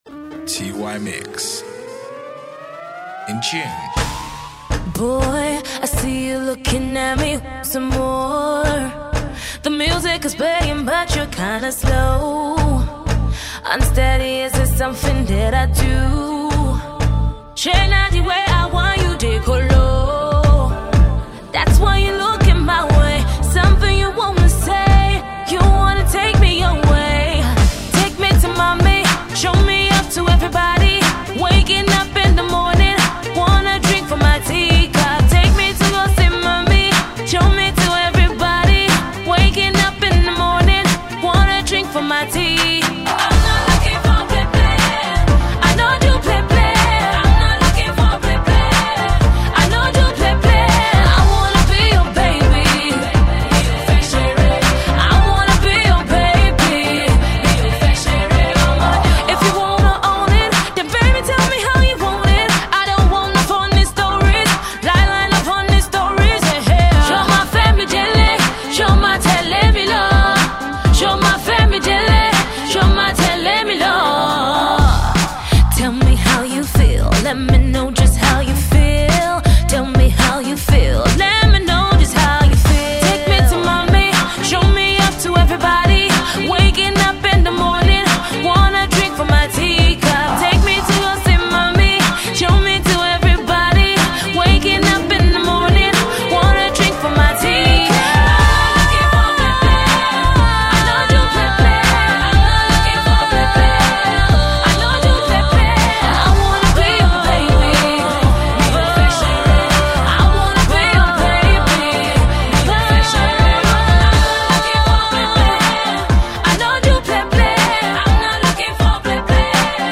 Afro Pop